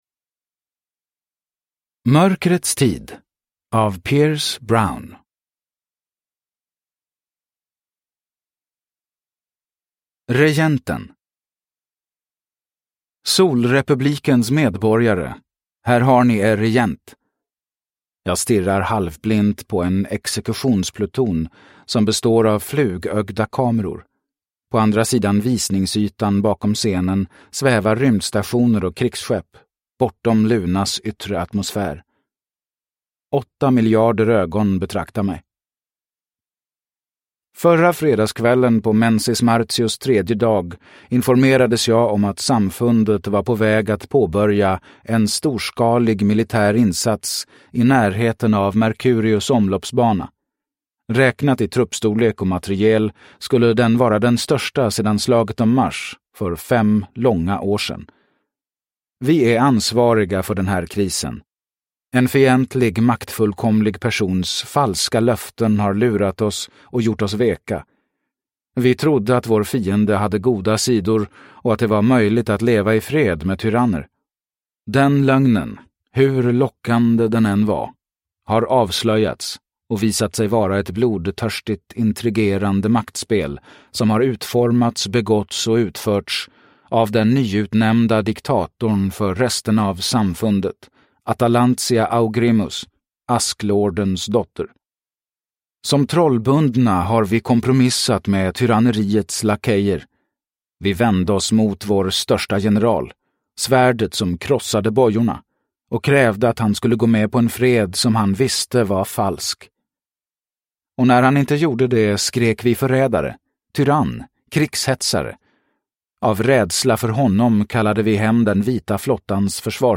Mörkrets tid – Ljudbok – Laddas ner